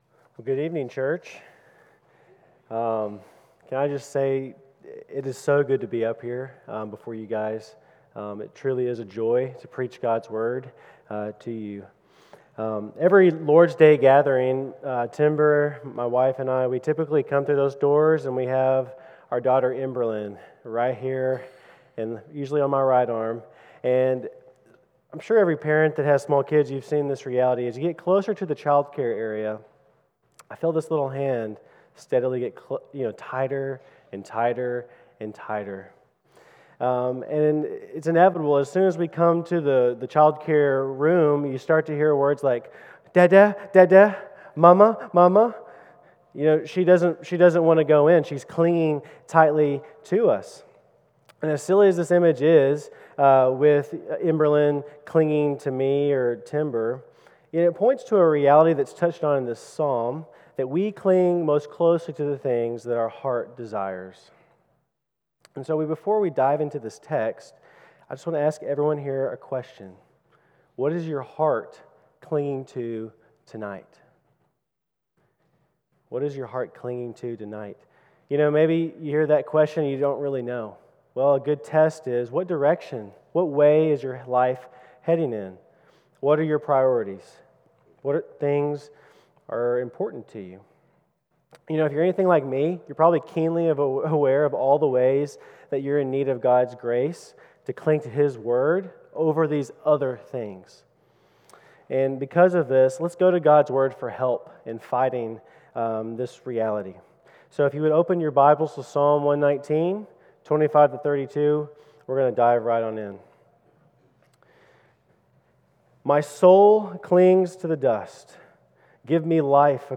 on the evening of June 1, 2025, at Chaffee Crossing Baptist Church in Barling, AR.